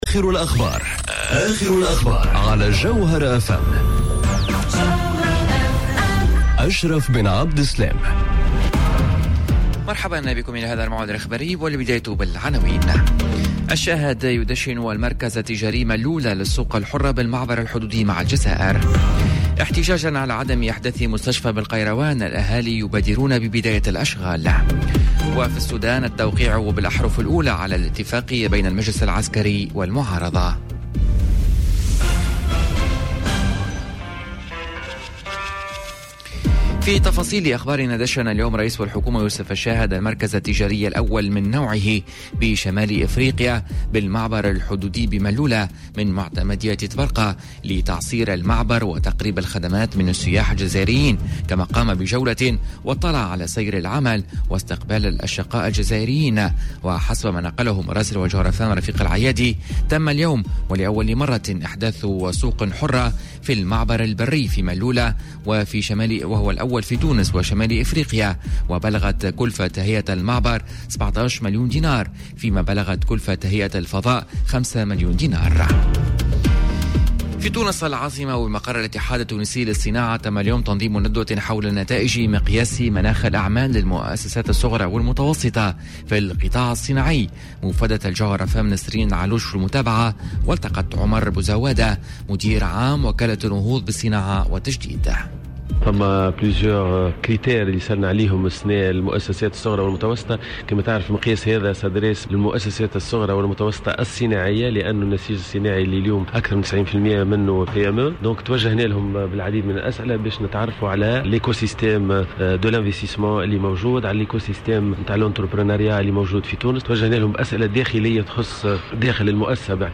نشرة أخبار منتصف النهار ليوم الإربعاء 17 جويلية 2019